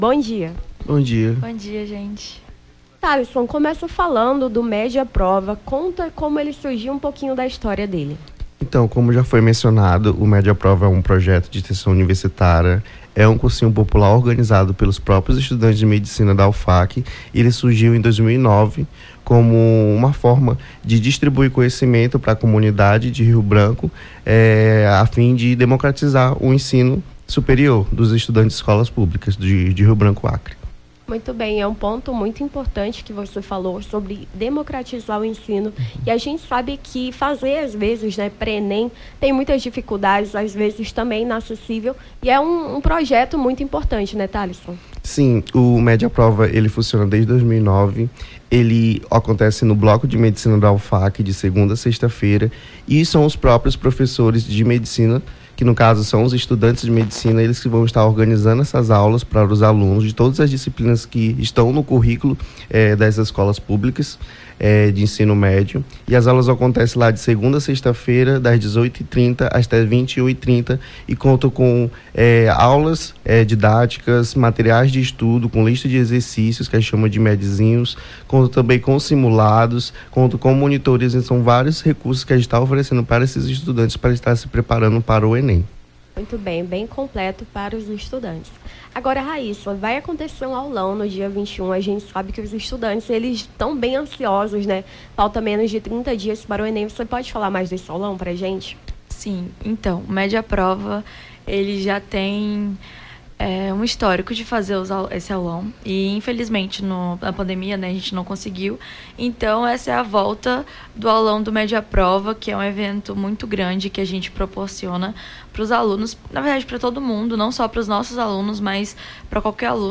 Nome do Artista - CENSURA - ENTREVISTA (MEDAPROVA) 09-10-23.mp3